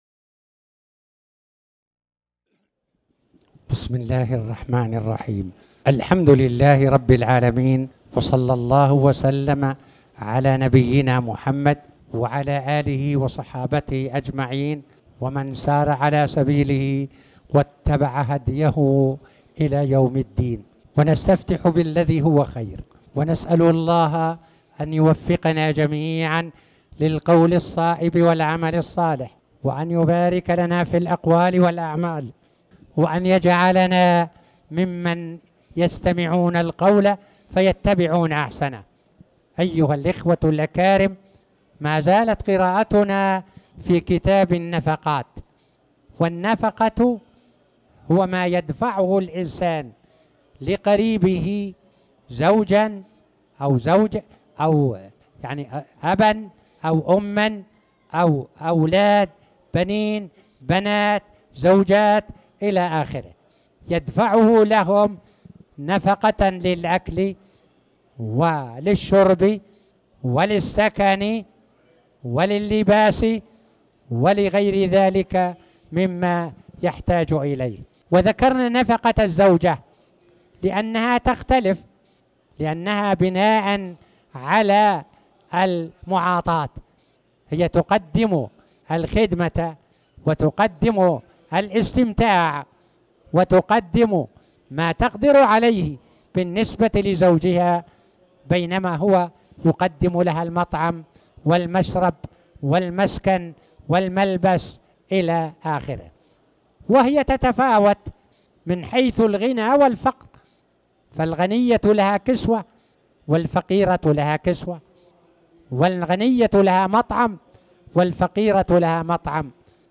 تاريخ النشر ١٦ جمادى الآخرة ١٤٣٩ هـ المكان: المسجد الحرام الشيخ